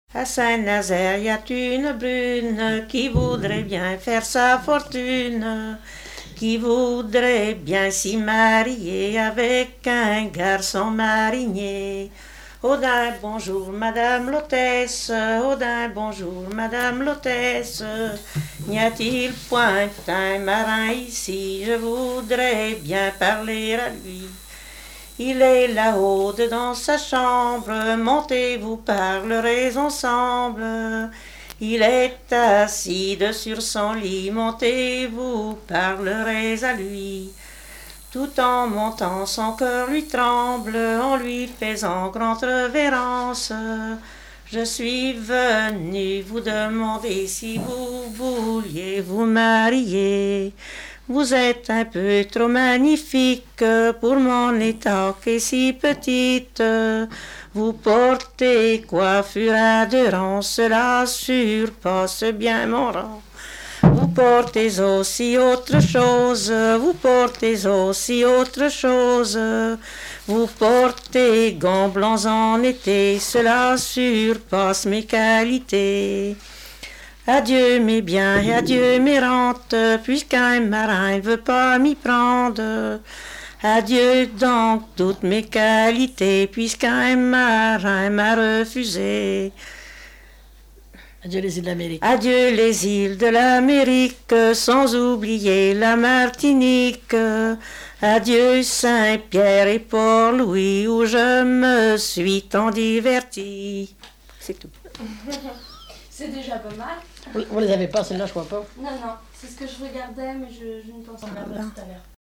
Mémoires et Patrimoines vivants - RaddO est une base de données d'archives iconographiques et sonores.
danse : ronde
chansons traditionnelles
Pièce musicale inédite